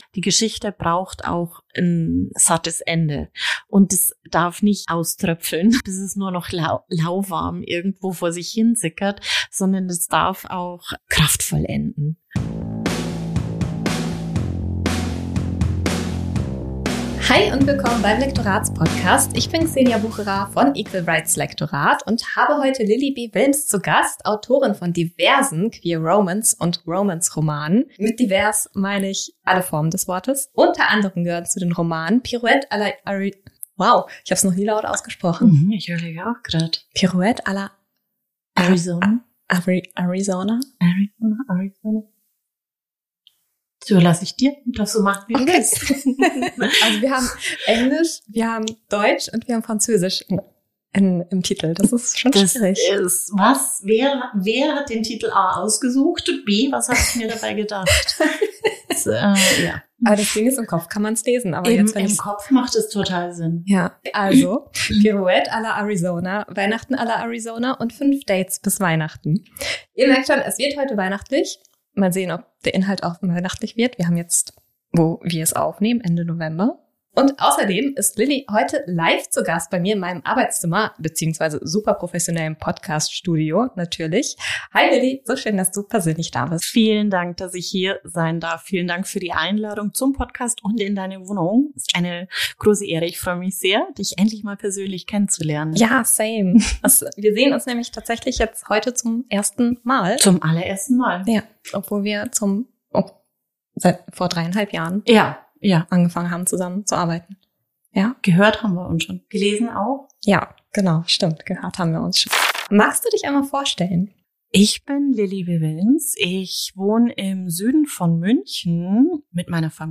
#13 Lektorin interviewt Autorin